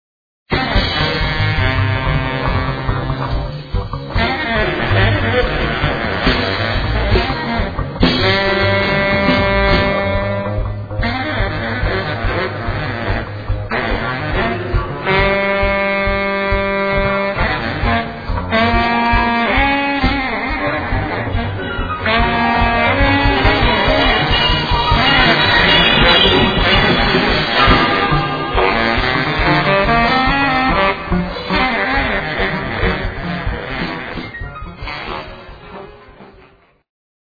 freejazz
baritone saxophone
el. doublebass
drums
steel, el.guitar
piano). Live from Prague [2002].